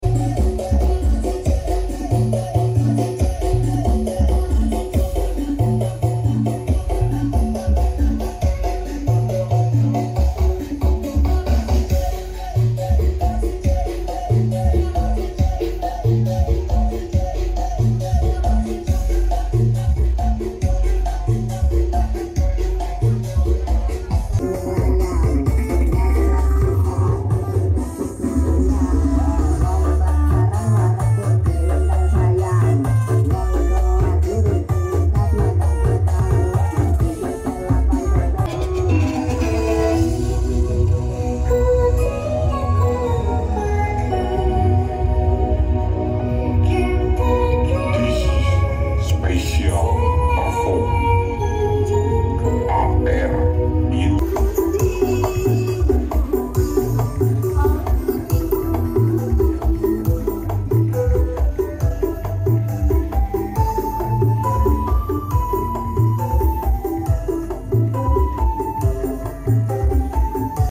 Karnaval Pagerwojo kesamben blitar....!!!